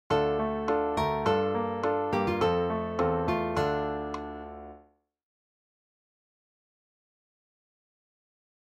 Dm  Gm9  F  Dm [